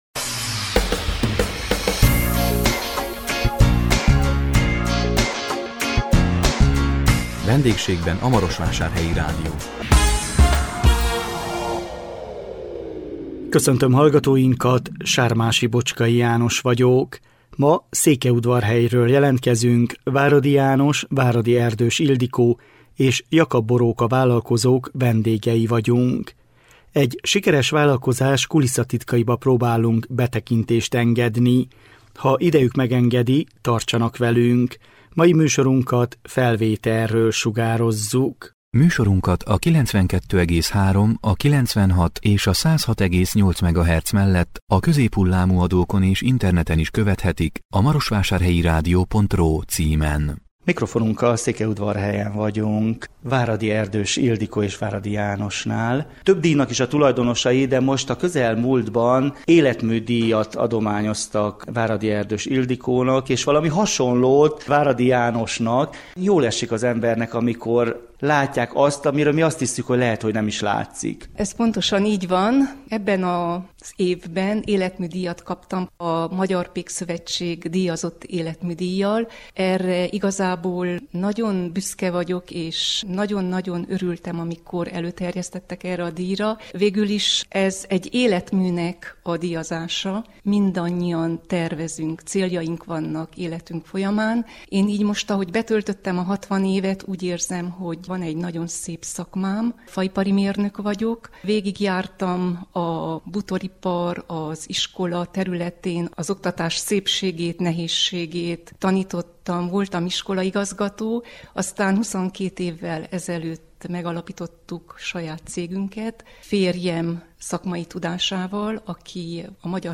A 2022 december 29-én közvetített VENDÉGSÉGBEN A MAROSVÁSÁRHELYI RÁDIÓ című műsorunkkal Székelyudvarhelyről jelentkeztünk